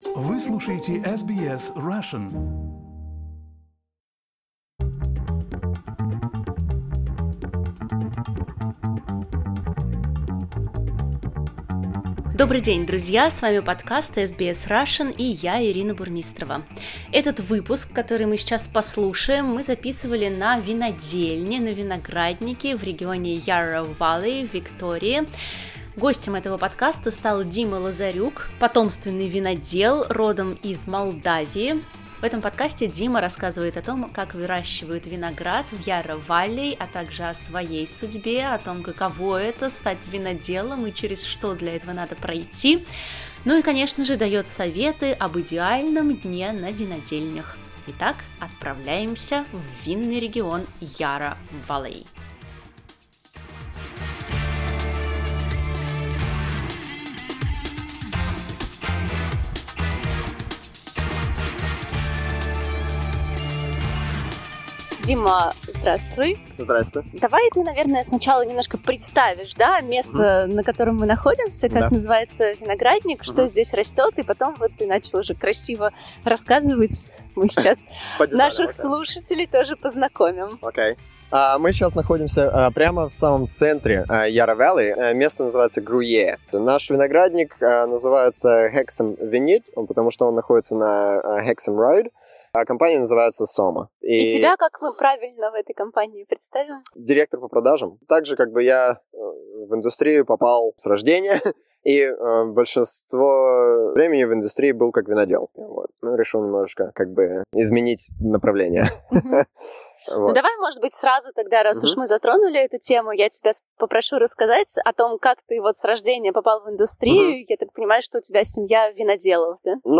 Этот подкаст мы записали на винограднике в долине Yarra Valley